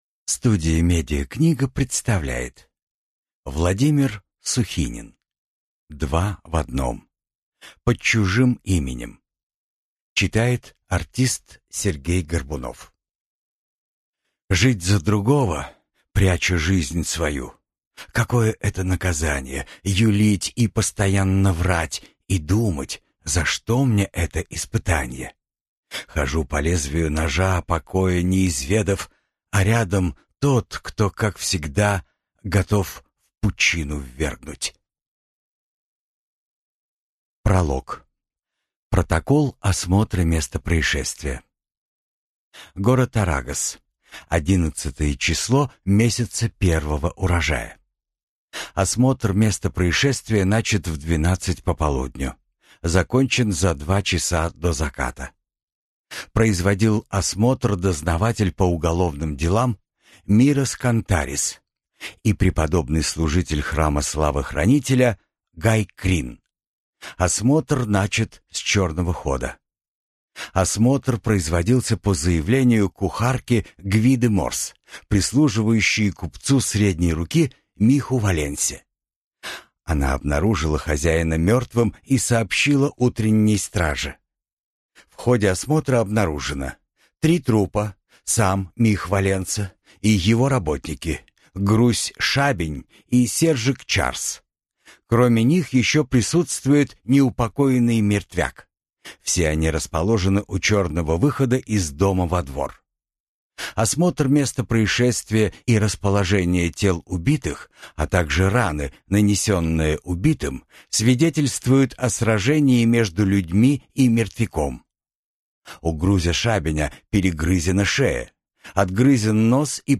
Аудиокнига Два в одном. Под чужим именем | Библиотека аудиокниг